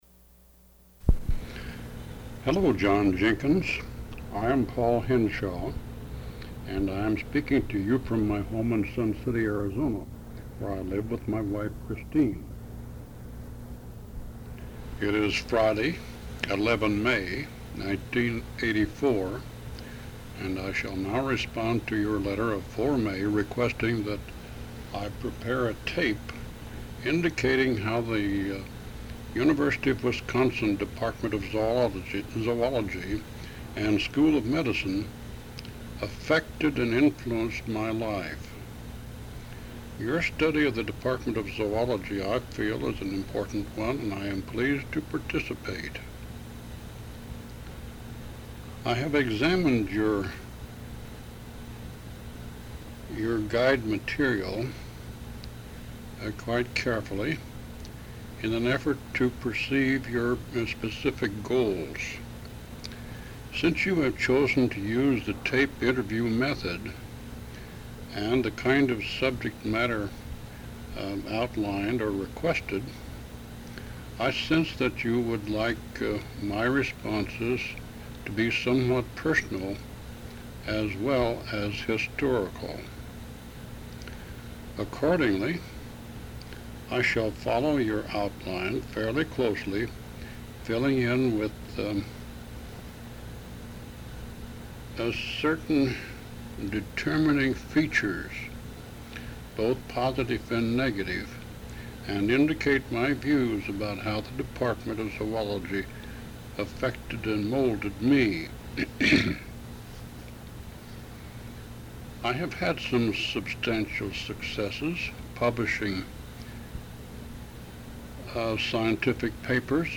Oral History Inteview